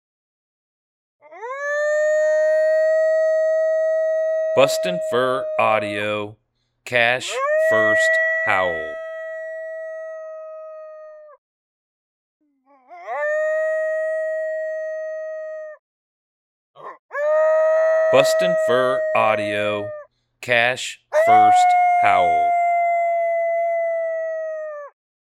2 Year old Male Coyote lone howling.